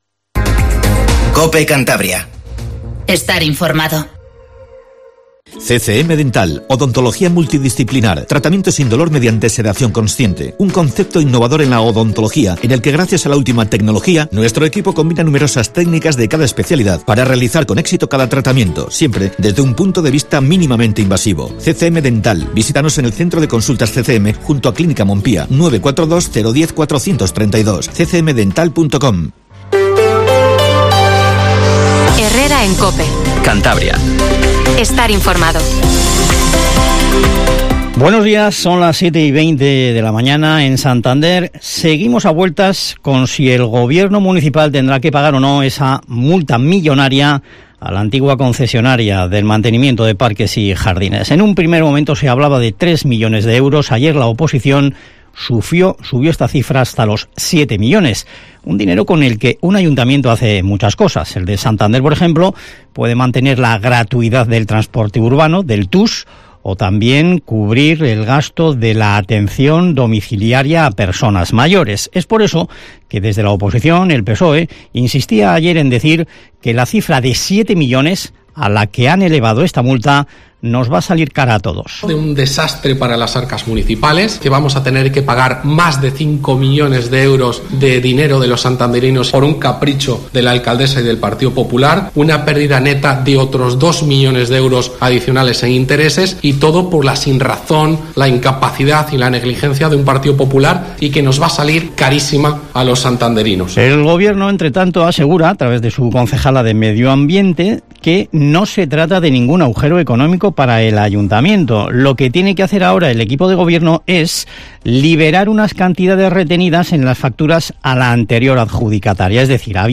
Informativo HERRERA en COPE CANTABRIA 07:20